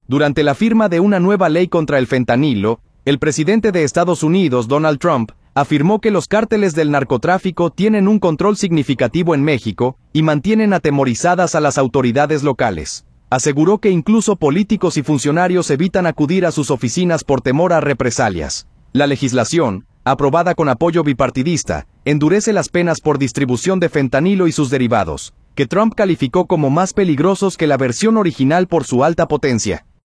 Durante la firma de una nueva ley contra el fentanilo, el presidente de Estados Unidos, Donald Trump, afirmó que los cárteles del narcotráfico tienen un control significativo en México y mantienen atemorizadas a las autoridades locales. Aseguró que incluso políticos y funcionarios evitan acudir a sus oficinas por temor a represalias.